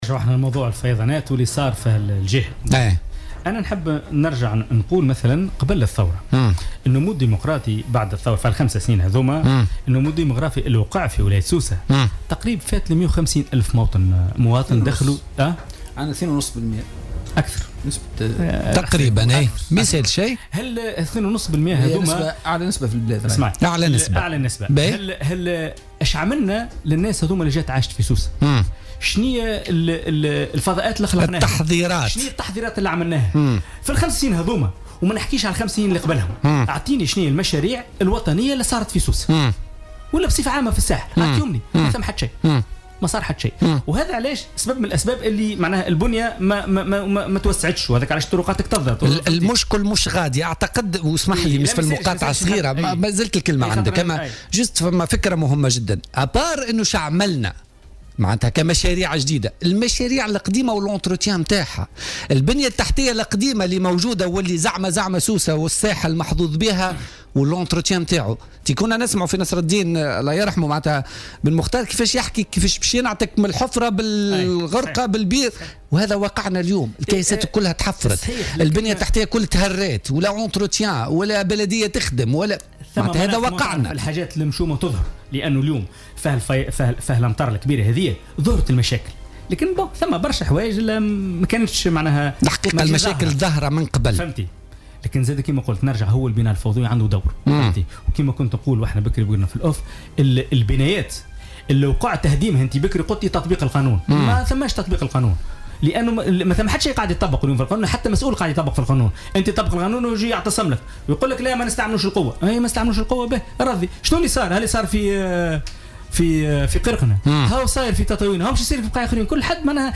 Lors de son intervention dans l'émission Politica de ce lundi 26 septembre 2016, le député d'Afek Tounes à l'Assemblée des Représentants du peuple (ARP), Hafedh Zouari, a appelé le chef du Gouvernement Youssef Chahed à effectuer une visite officielle au gouvernorat de Sousse.